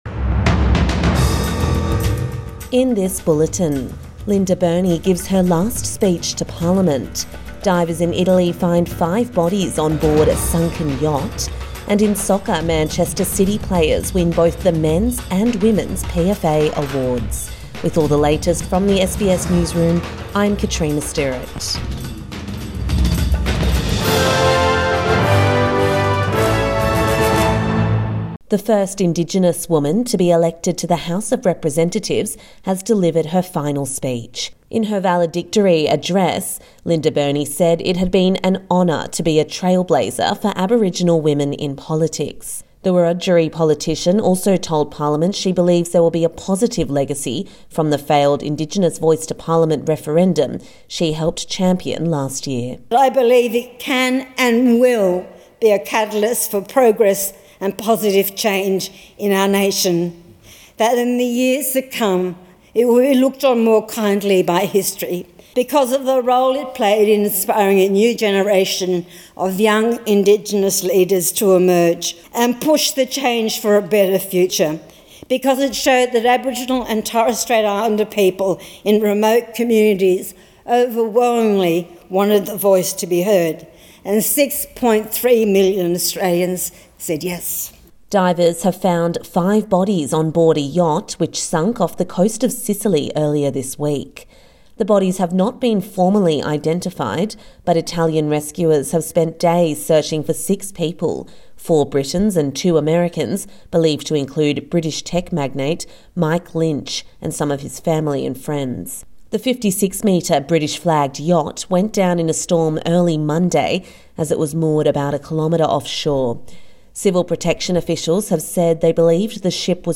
Morning News Bulletin 22 August 2024